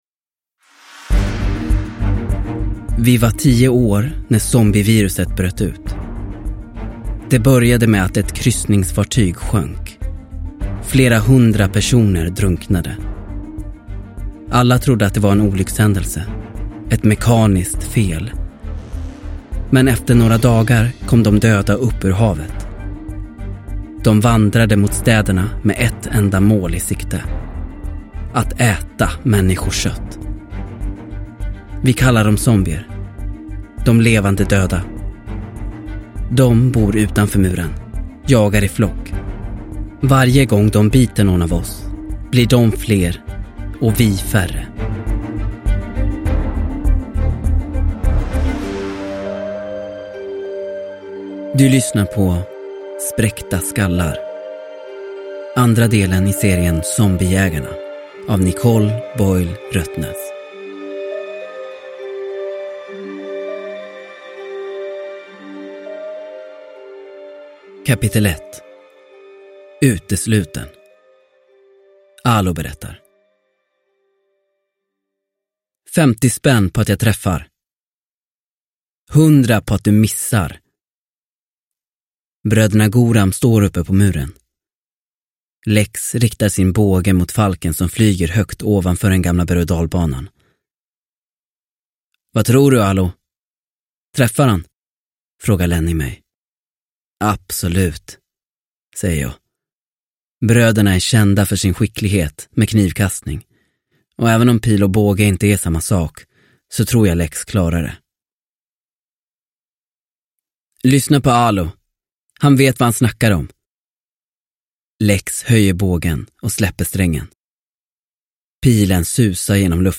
Spräckta skallar – Ljudbok – Laddas ner